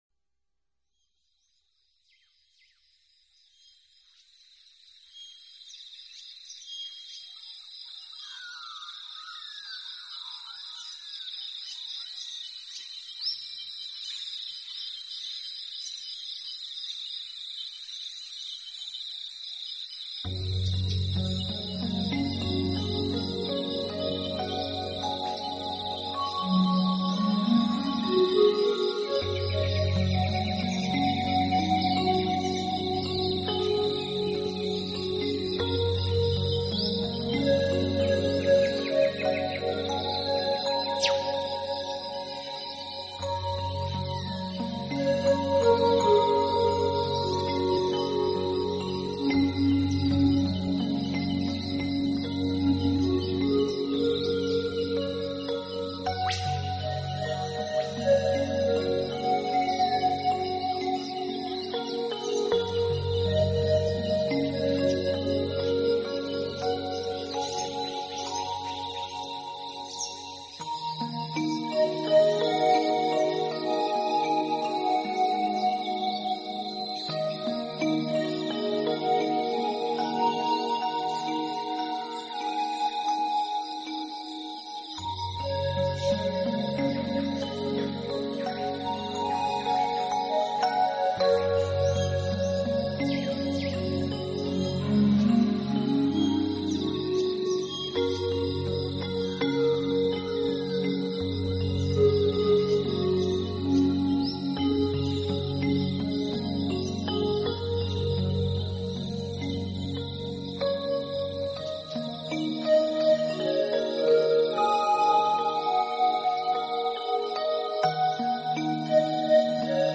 新世纪纯音乐
专辑语言：纯音乐